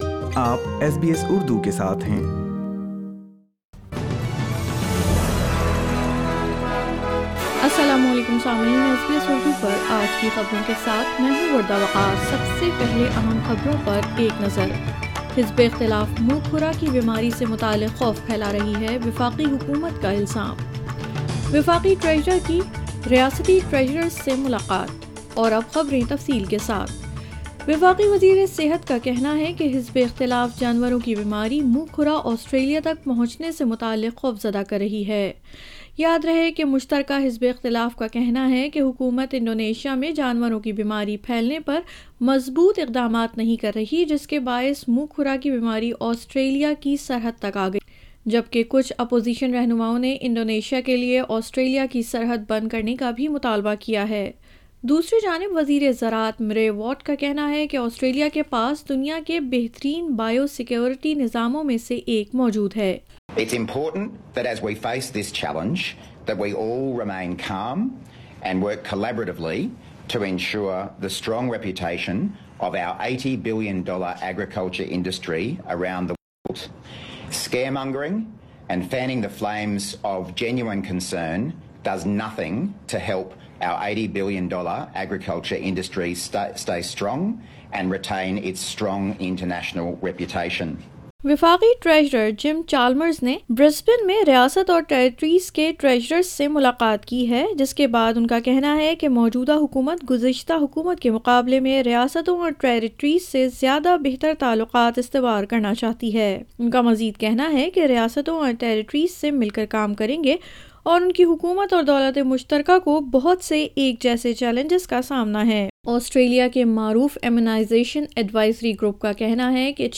SBS Urdu News 22 July 2022